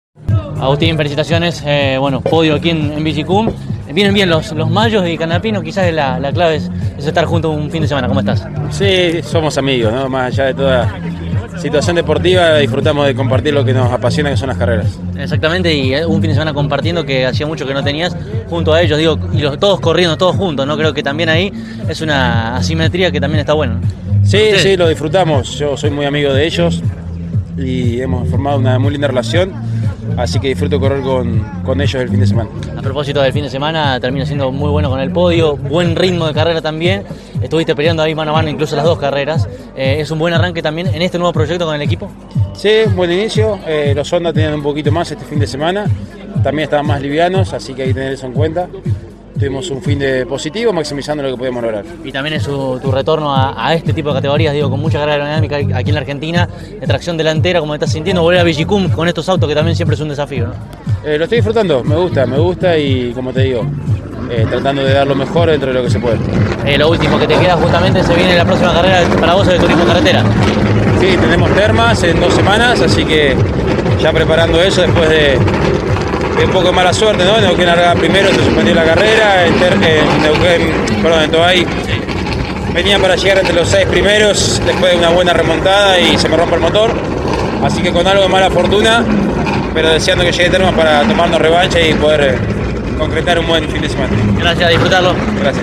El Turismo Carretera 2000 corrió, este fin de semana en el «Circuito San Juan-Villicum», su segunda fecha de la historia y, tras concluir la competencia de ayer domingo, los protagonistas del podio pasaron por los micrófonos de CÓRDOBA COMPETICIÓN.